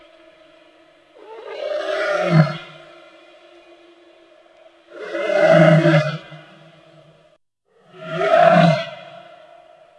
Звук медведя, который ревёт